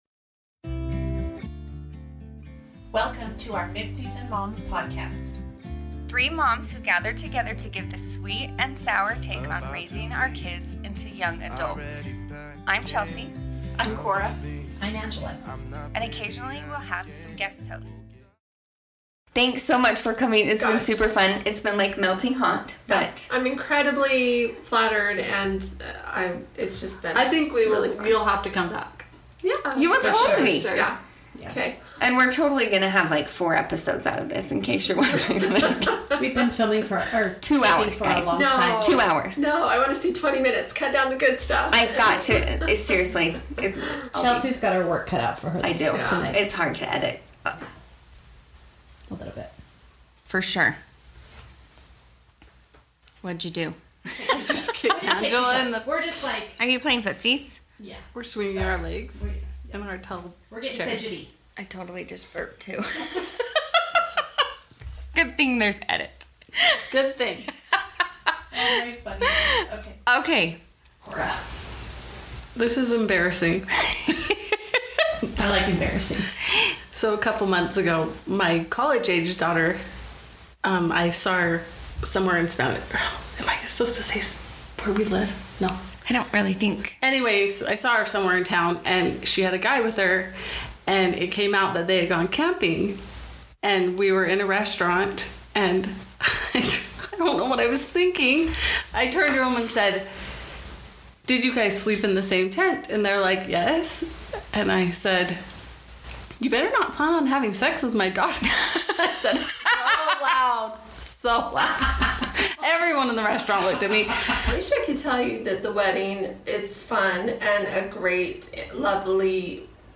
So who knew podcasting was so funny? And us moms tend to forget we are recording when we start talking.